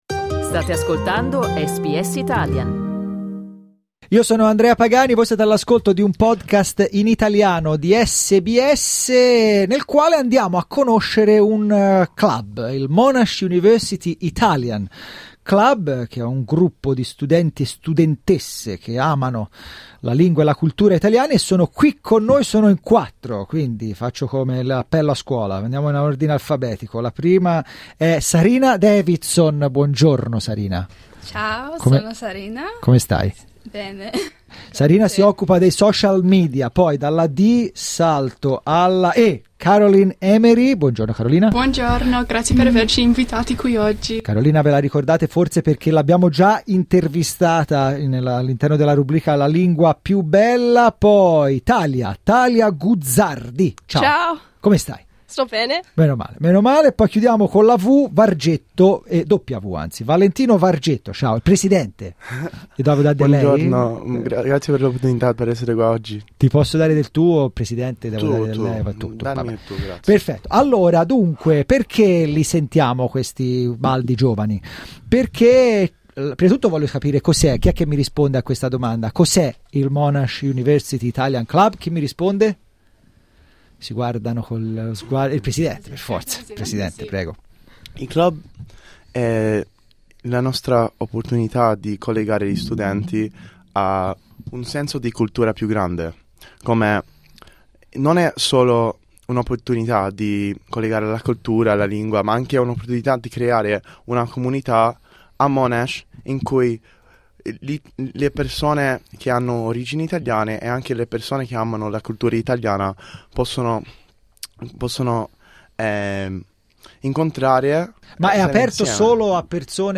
Ascolta l'intervista ai ragazzi del Monash Italian Club cliccando 'play' in alto La locandina dell'evento del MUIC del 20 settembre.